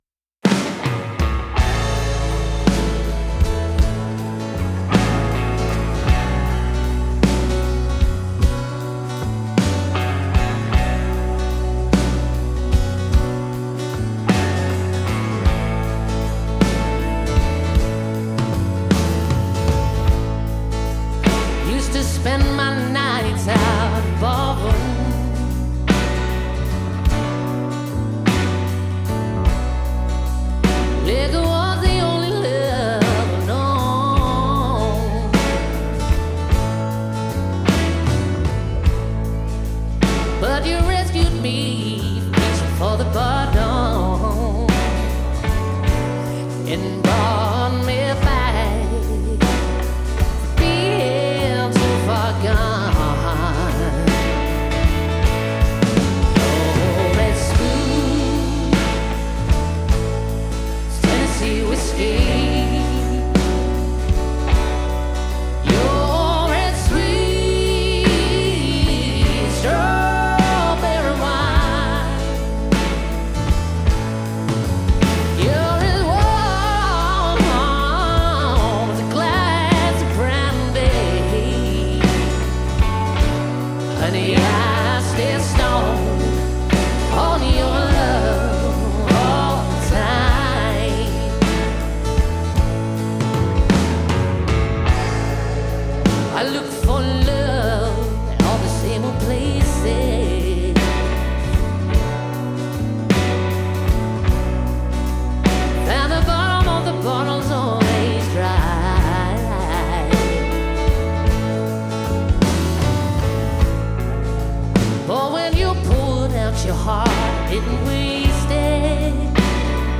• Powerful lead vocals and tight harmonies
• Full live band setup: guitars, bass, drums